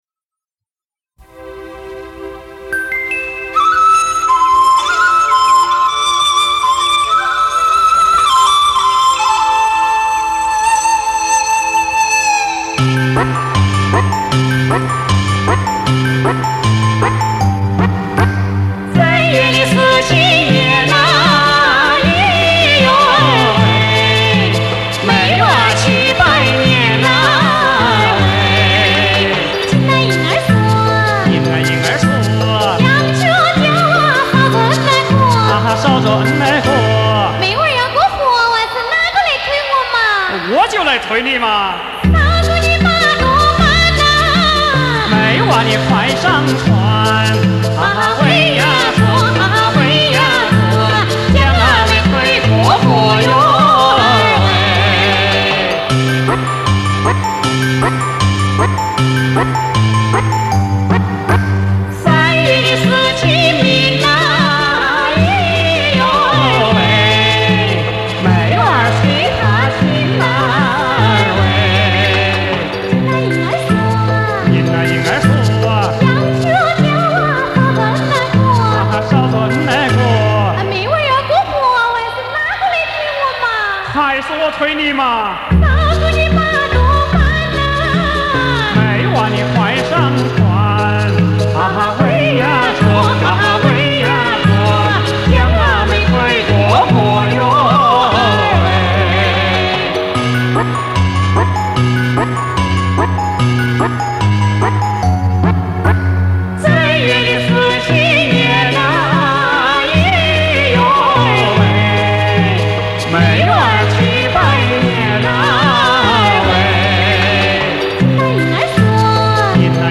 湖北利川民歌